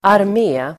Uttal: [arm'e:]